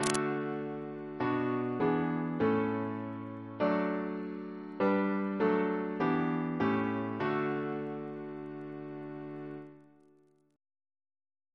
CCP: Chant sampler
Single chant in D Composer: Thomas Kelway (1695-1749) Reference psalters: ACB: 49; H1982: S14; OCB: 111; PP/SNCB: 156; RSCM: 189